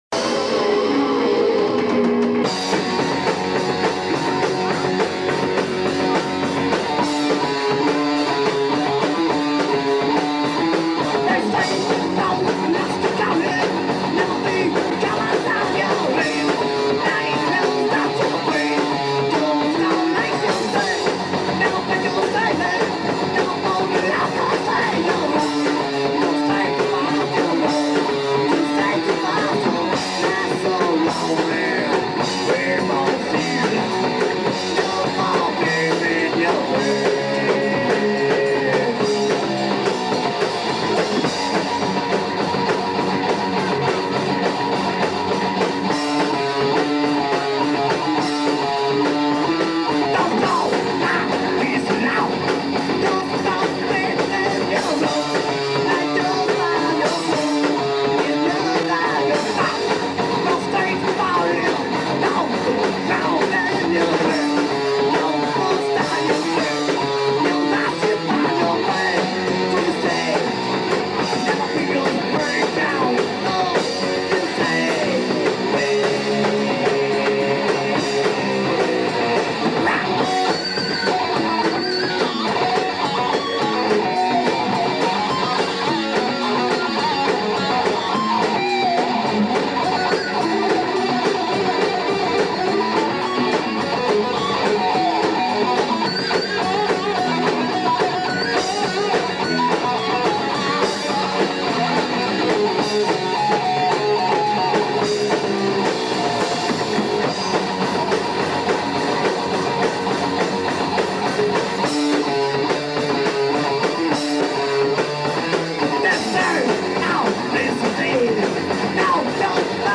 I've attached an MP3 of an original song recorded live with my old band 'Tantrum' at a bar on Halloween Night back in '97.
I didn't even have any lyrics written for it yet, and sang it totally different on that night (mush mouthed it) than I did the two times we played at practice.